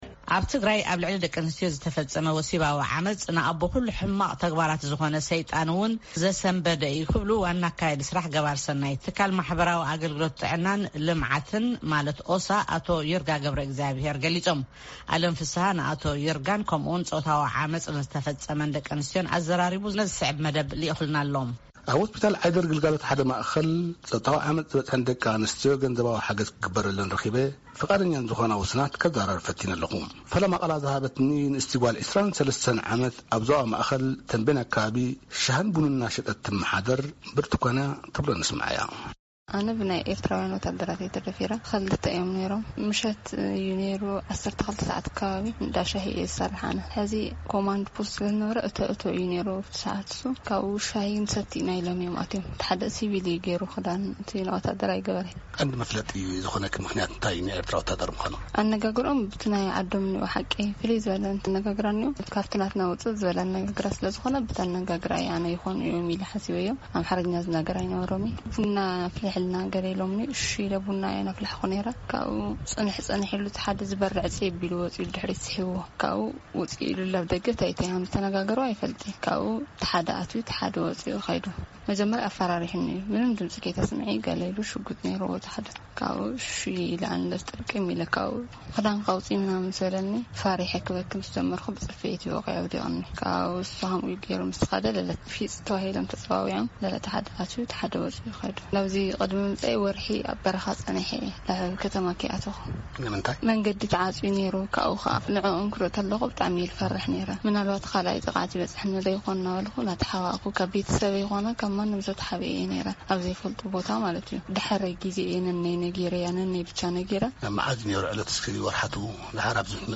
ቃለ መጠይቕ ምስ ኣብ ክልል ትግራይ ጾታዊ ዓመጽ ዘጋጠመን ደቂ ኣንስትዮን አካያዲ ስራሕ ዝሕግዝን ትካልን